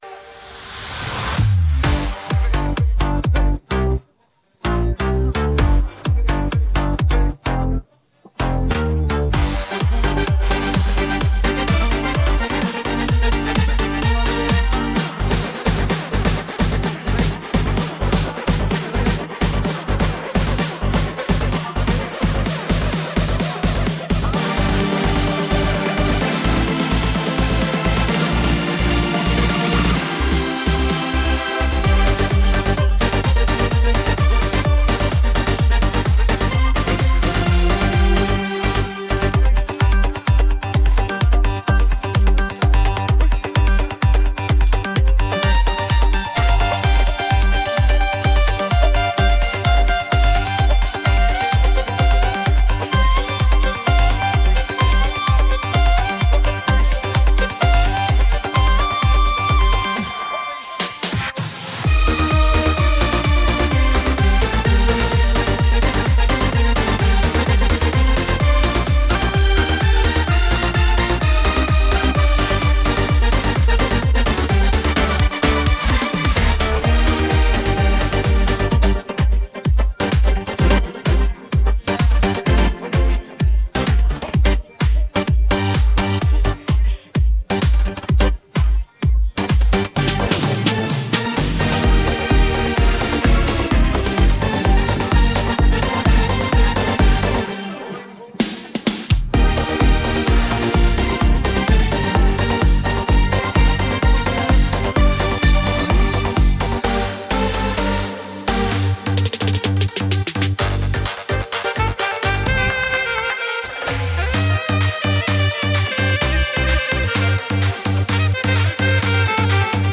פלייבק הפוך ששומעים רק את הזמר